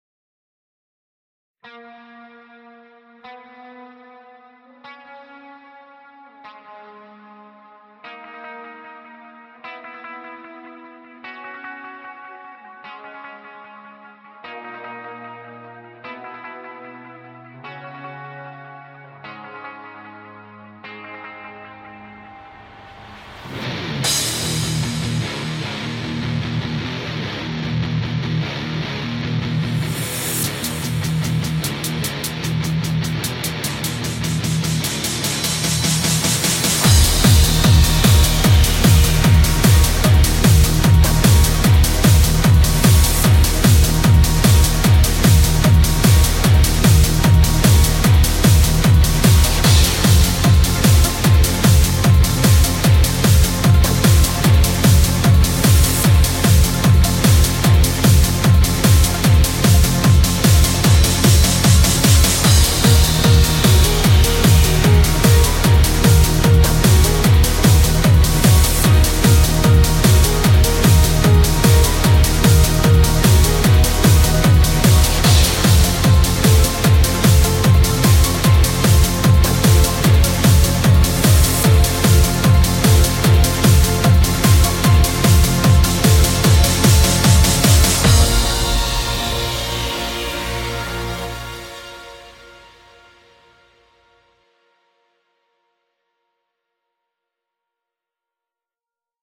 Guitars